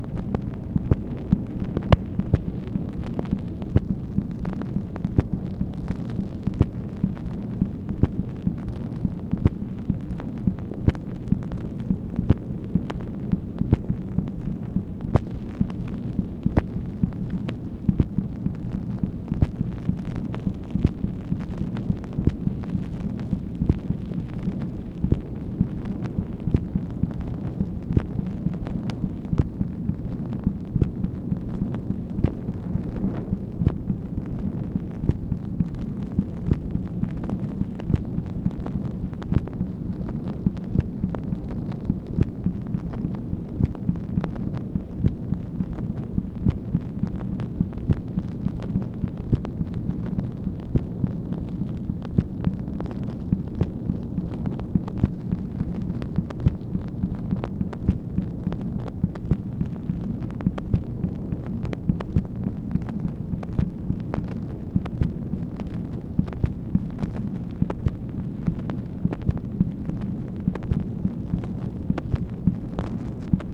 MACHINE NOISE, August 26, 1965
Secret White House Tapes | Lyndon B. Johnson Presidency